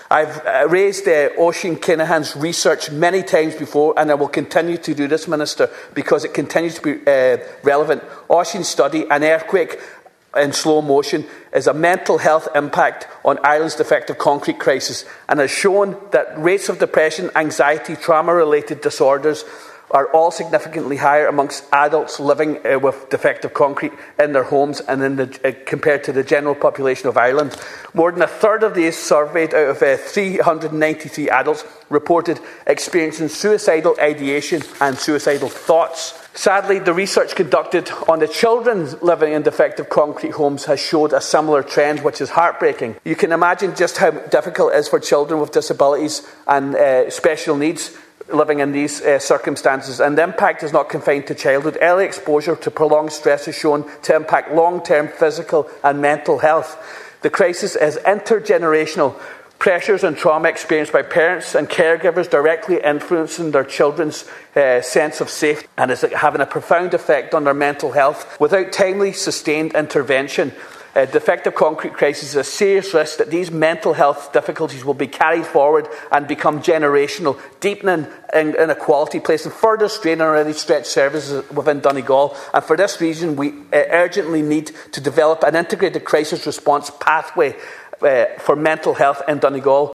Deputy Ward was speaking during a Sinn Fein motion requiring all Model 3 and Model 4 hospitals to provide a dedicated mental health emergency room, separate from emergency departments.